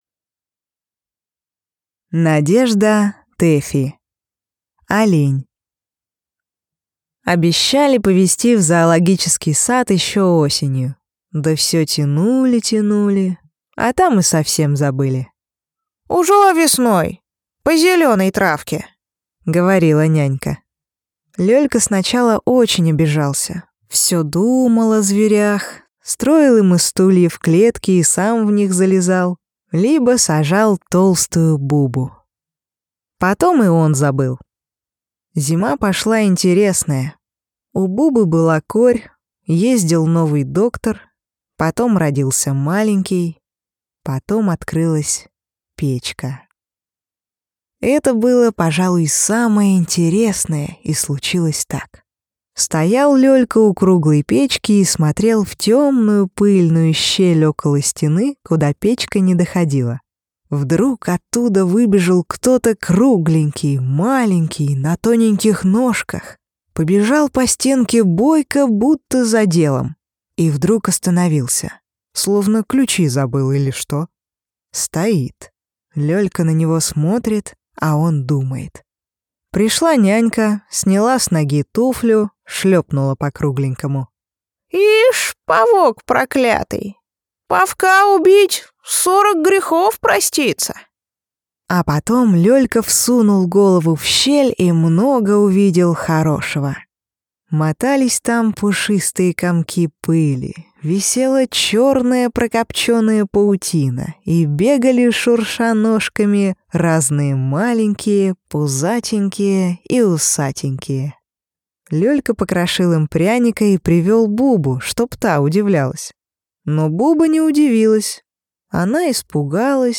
Аудиокнига Олень | Библиотека аудиокниг